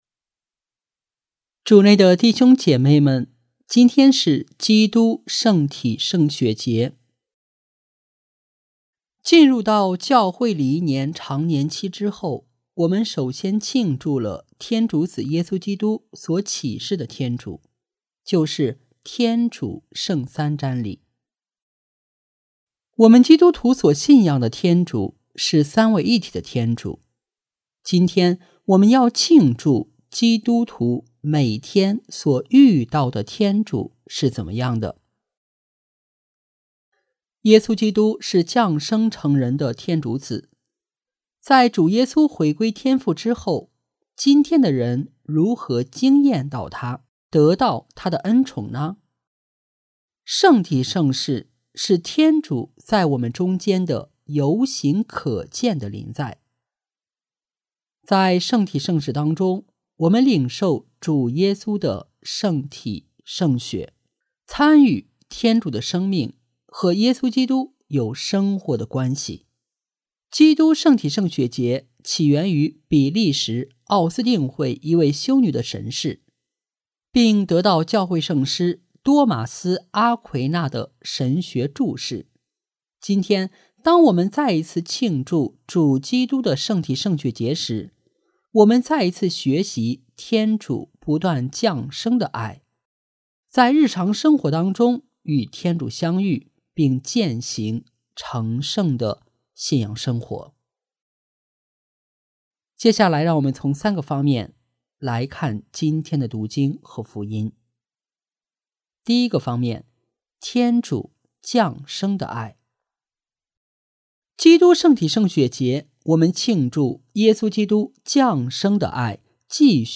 【主日证道】| 天主降生的爱（基督圣体圣血节）